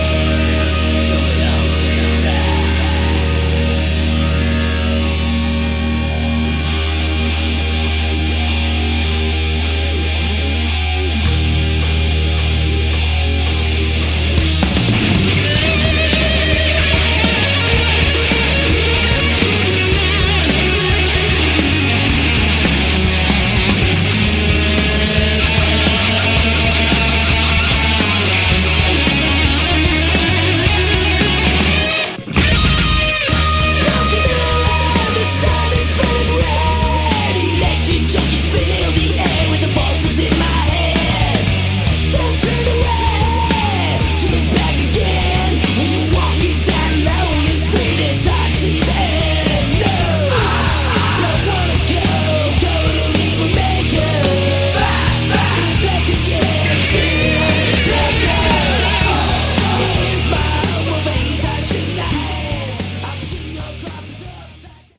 Category: Hard Rock
bass, backing vocals
guitars, keys, harp
lead and backing vocals